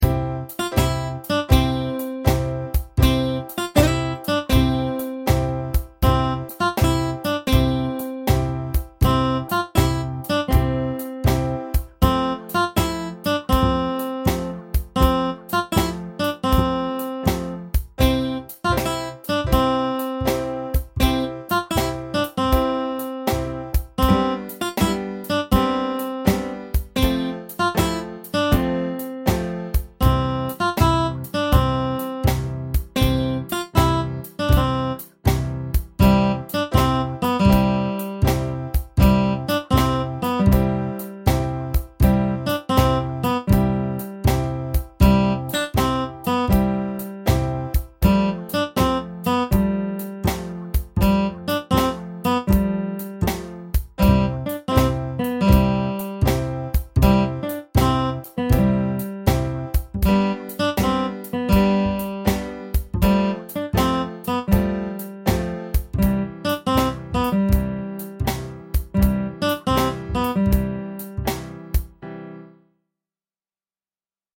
Updated version of Blues track with drum 2